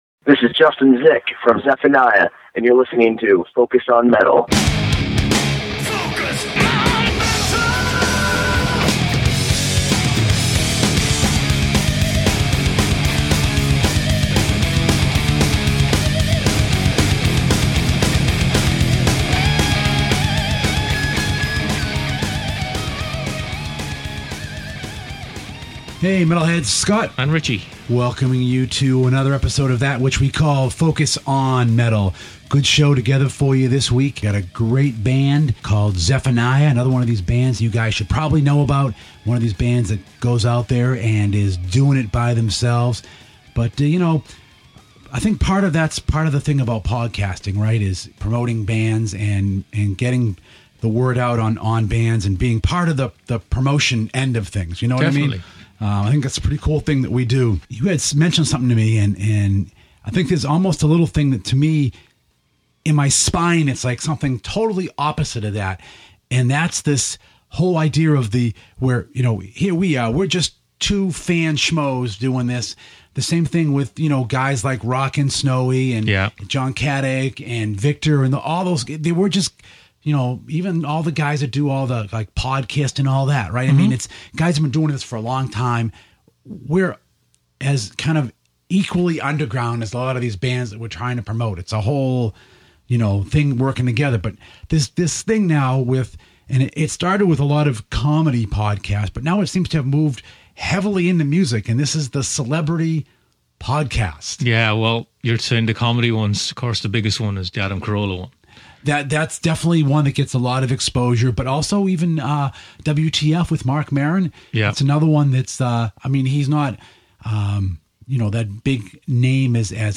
Track of the week is also from a band that has returned after a long hiatus.